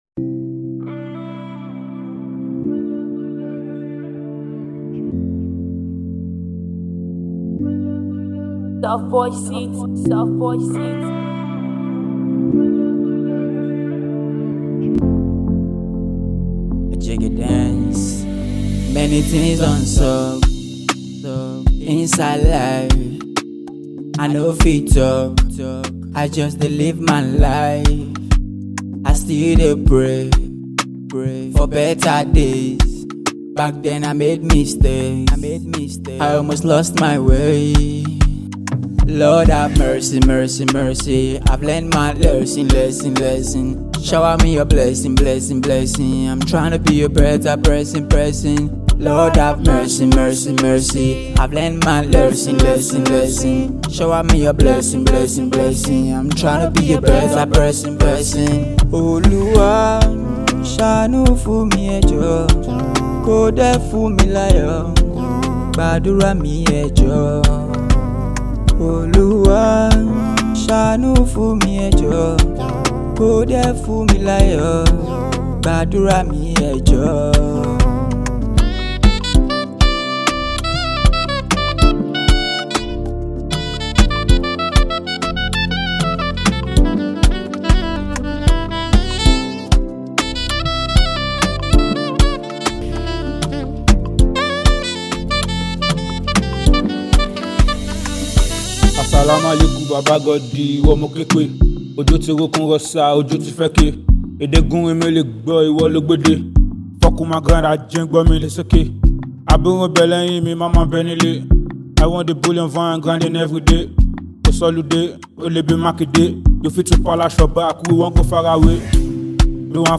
a blend of Afro-pop and hip-hop influences
brings a gritty yet melodic flow